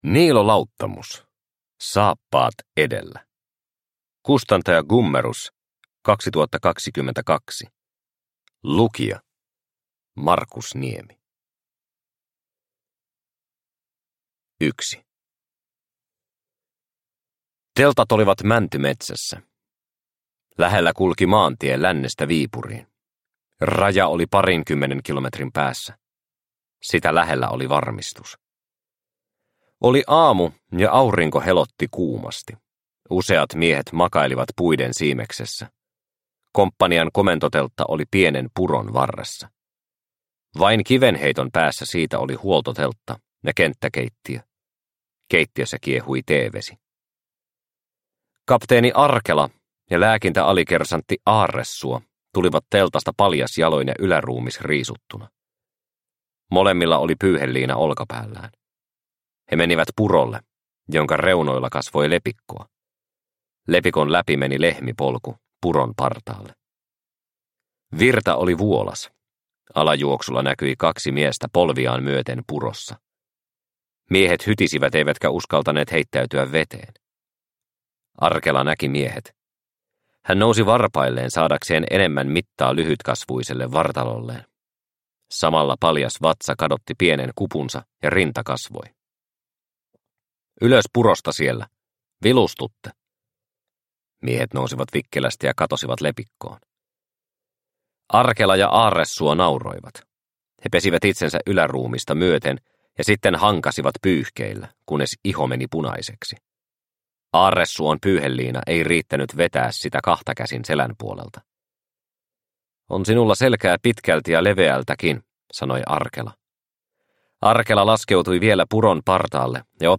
Saappaat edellä – Ljudbok – Laddas ner